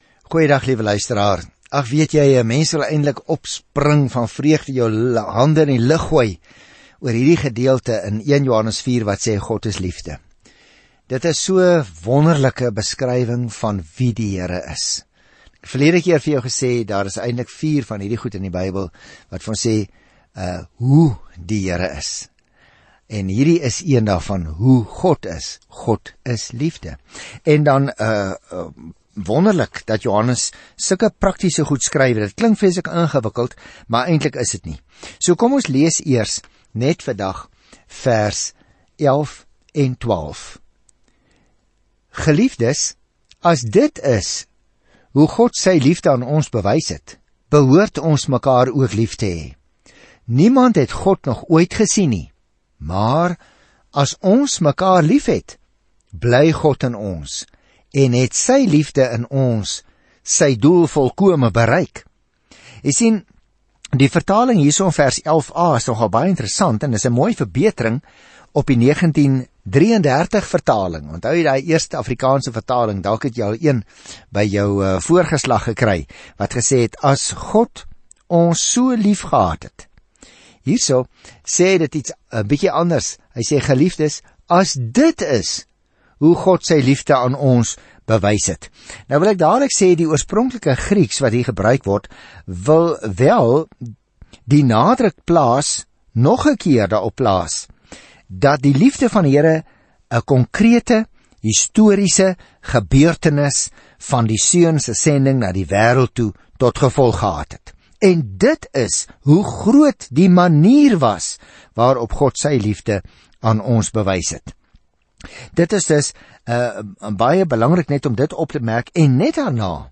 Skrif 1 JOHANNES 4:11-16 Dag 21 Begin met hierdie leesplan Dag 23 Aangaande hierdie leesplan Daar is geen middeweg in hierdie eerste brief van Johannes nie - óf ons kies lig óf duisternis, waarheid tot leuens, liefde of haat; ons omhels die een of die ander, net soos ons die Here Jesus Christus óf glo óf verloën. Reis daagliks deur 1 Johannes terwyl jy na die oudiostudie luister en uitgesoekte verse uit God se woord lees.